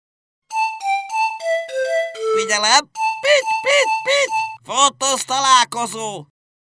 . SMS hangok .
Figyelem_pritty
Figyelem_pitty_pitty.mp3